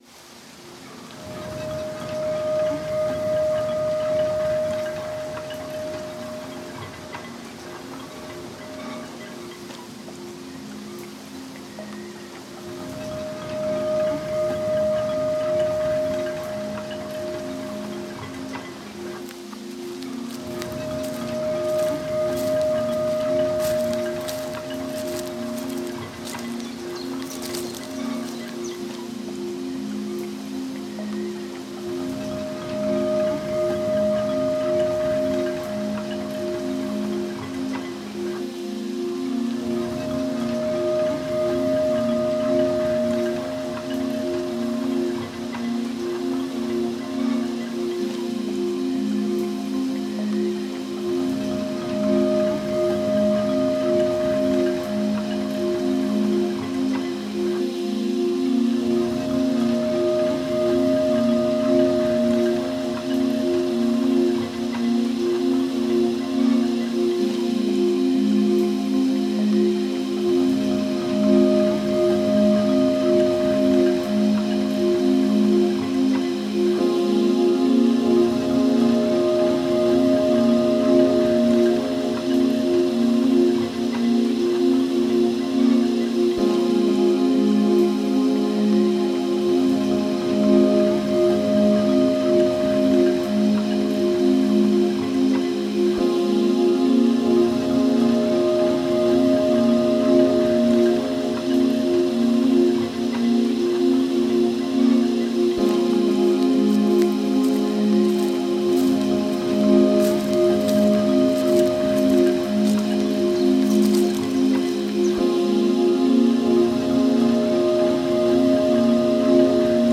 Нью эйдж Медитативная музыка
New Age